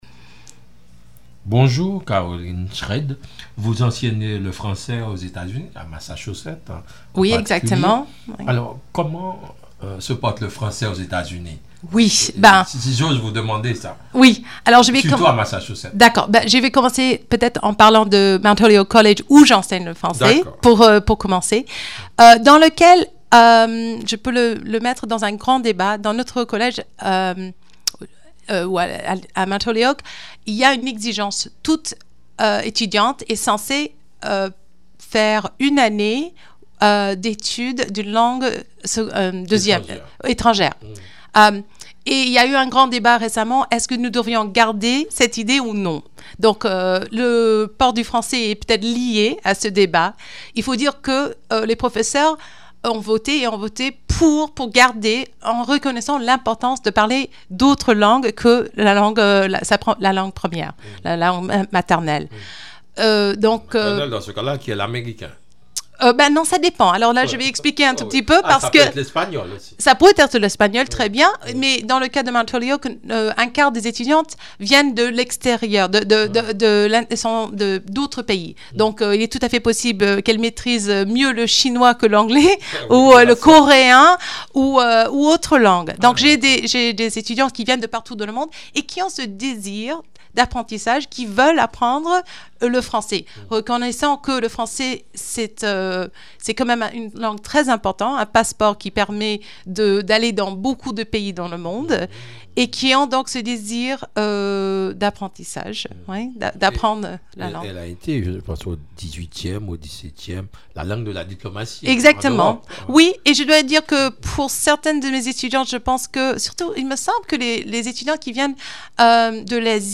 À Haute Voix, La Chronique
Invitée: